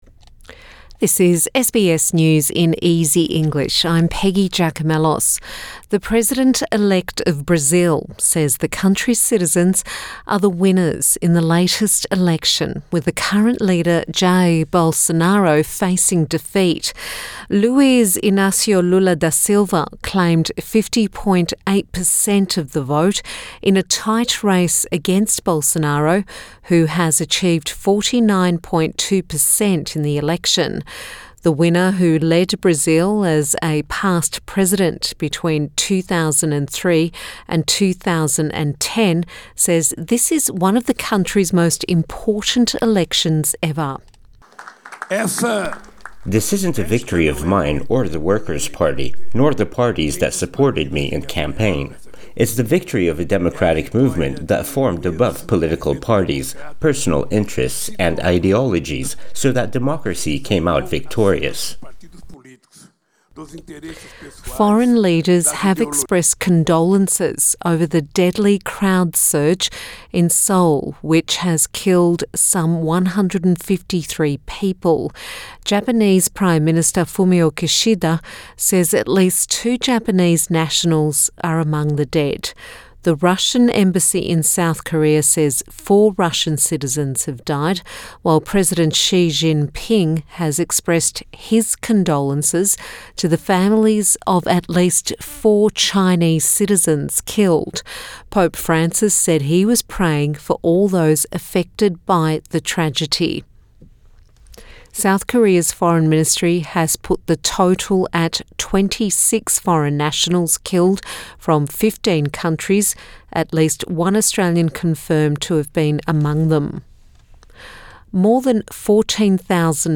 SBS News in Easy English